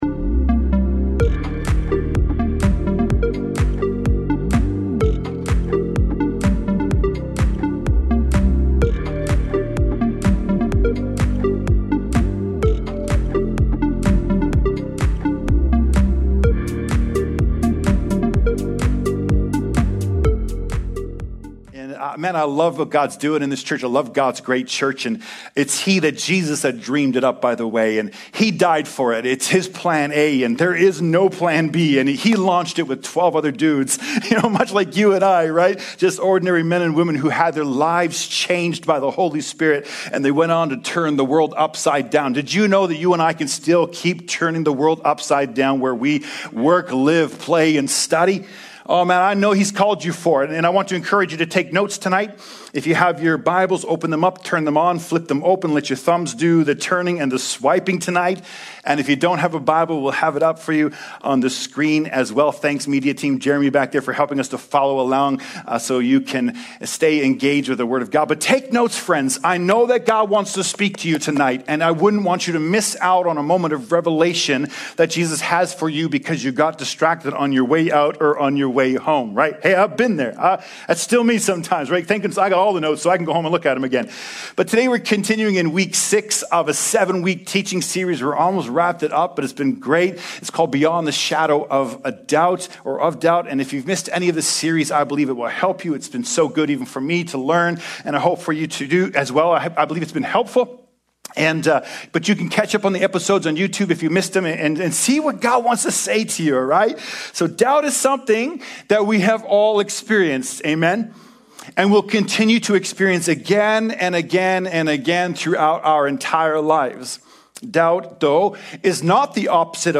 2024 Current Sermon What About God and Science?